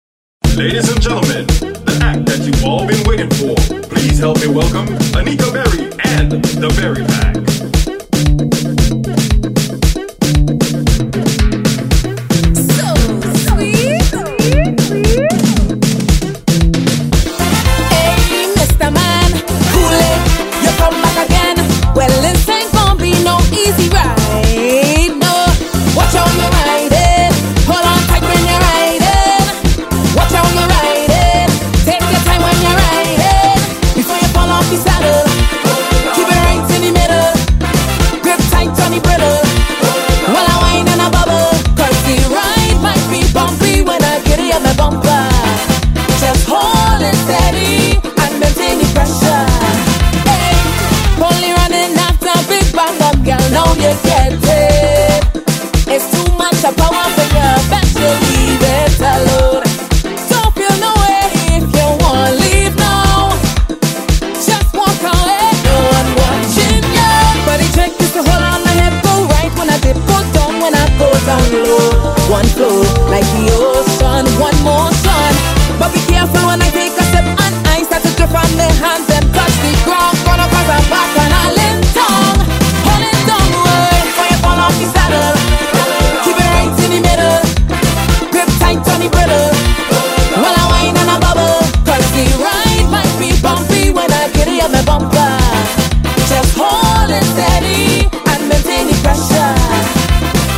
THE BEST SOCA RIDDIMS IN 2025 - THE BEST HITS